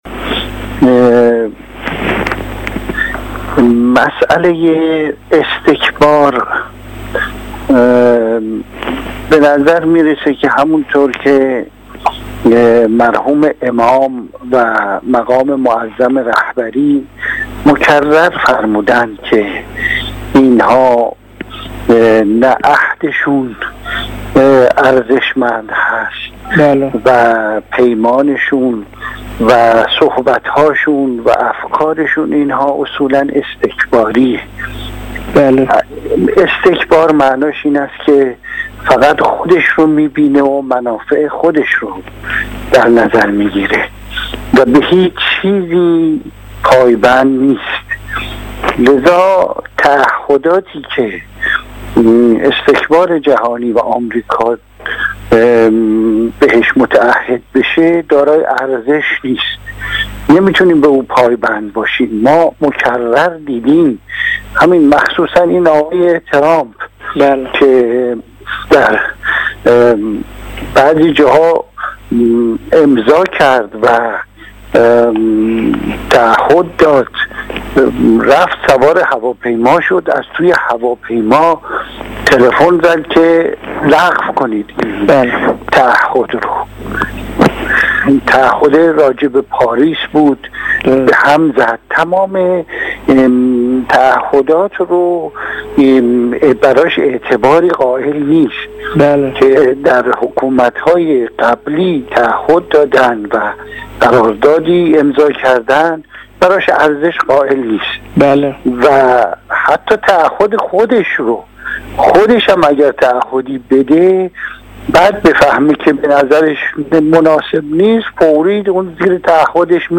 در گفت وگو با رسا